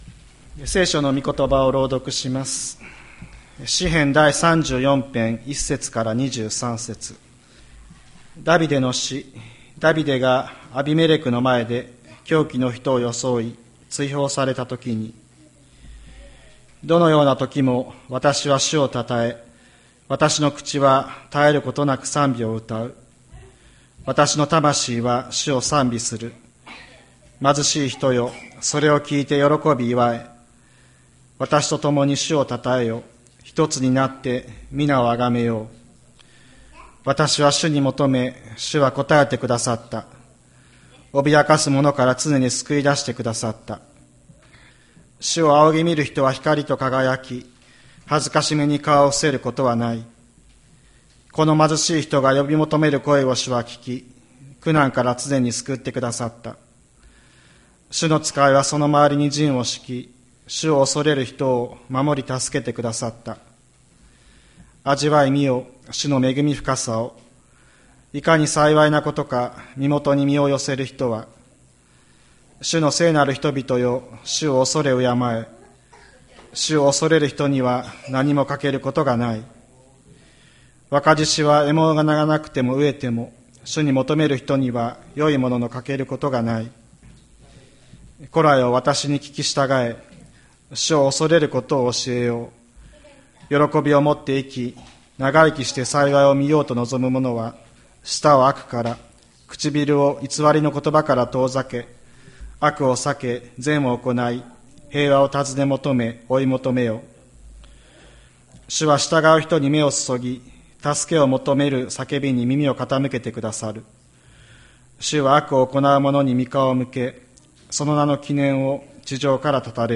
2024年10月06日朝の礼拝「味わい、見よ」吹田市千里山のキリスト教会
千里山教会 2024年10月06日の礼拝メッセージ。